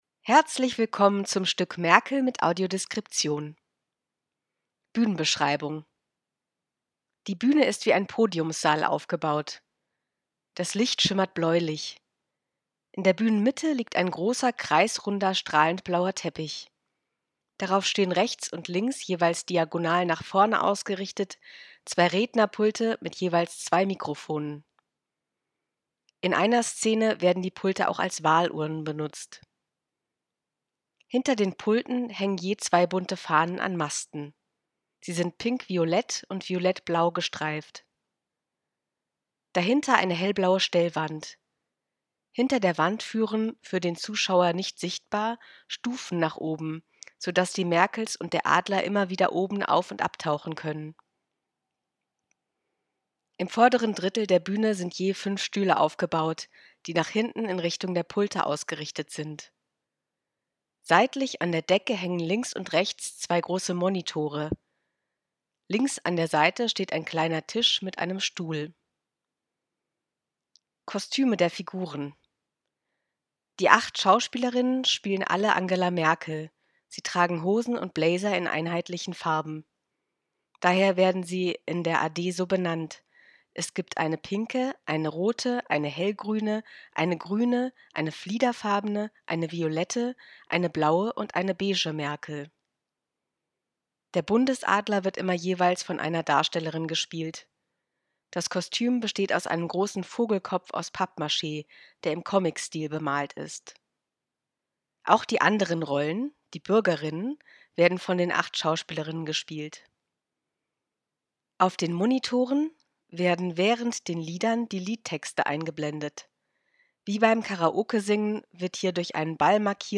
Sprechen AD: